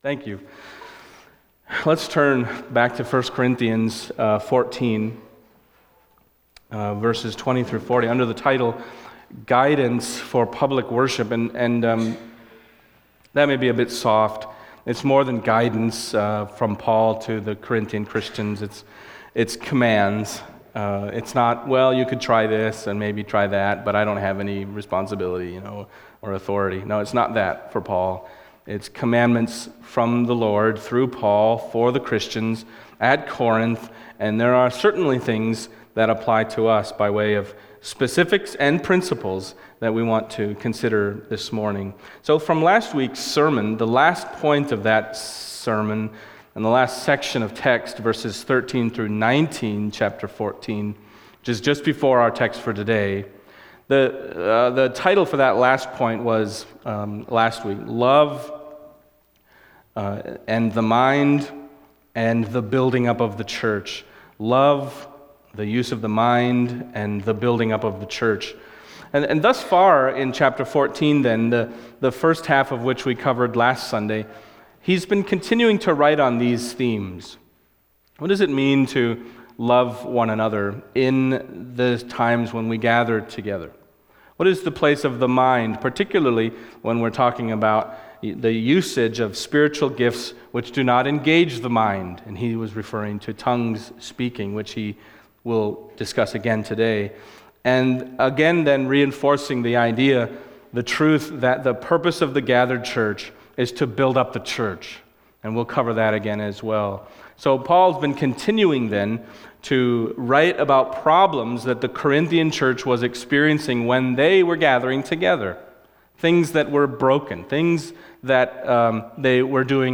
Passage: 1 Corinthians 14:20-40 Service Type: Sunday Morning